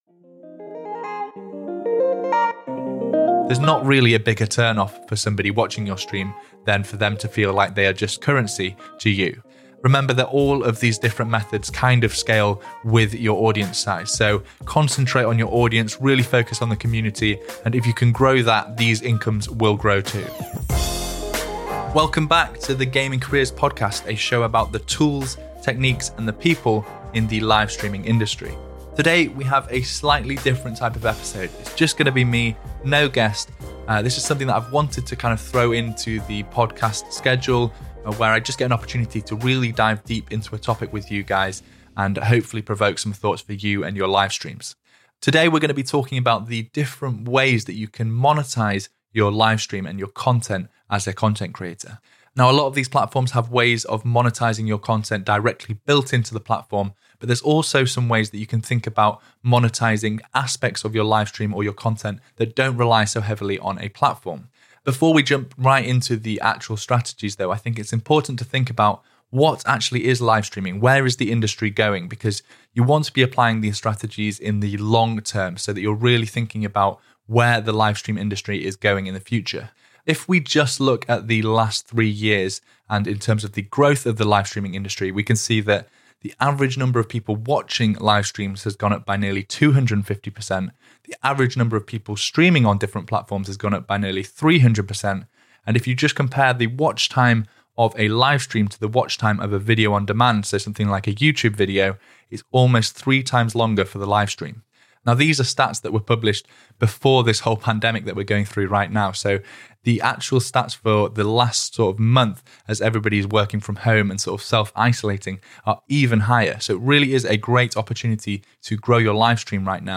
In this solo episode, I discuss the best ways to monetize your livestreams on Twitch, Mixer, YouTube, or any other platform. These tips apply from small streamers just starting out, to partnered streamers that have been streaming full-time for years.